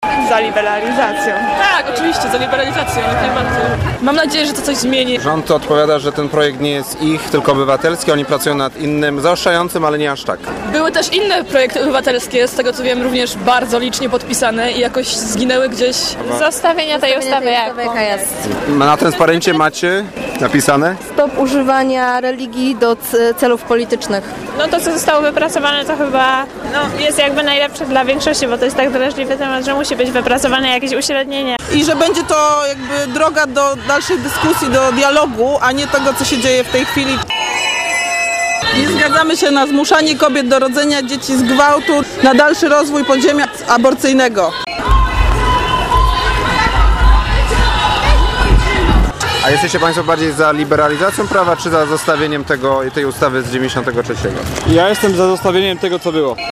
Nasz reporter pytał protestujących czy przyszli z hasłem liberalizacji przepisów dotyczących możliwości przerywania ciąży czy chcą utrzymania stanu prawnego, który obowiązuje w Polsce od 1993 roku.
mru7g7t4ybohl71_czarny-protest-poznan.mp3